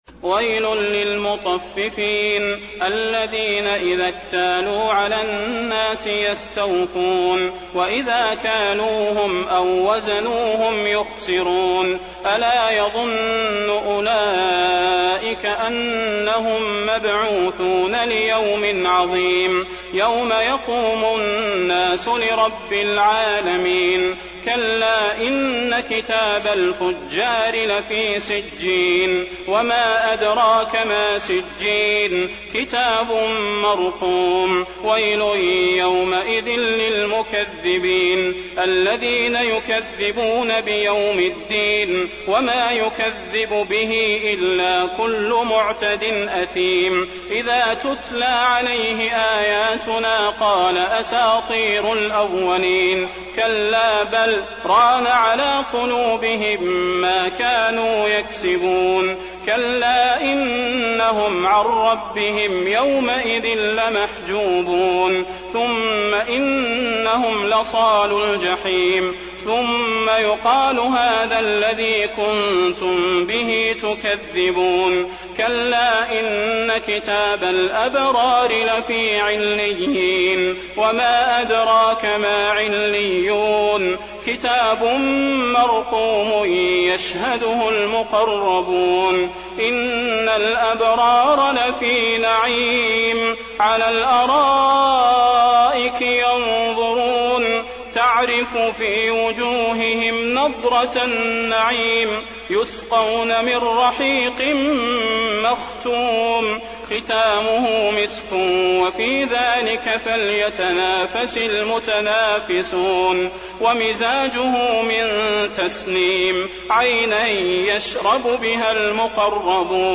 المكان: المسجد النبوي المطففين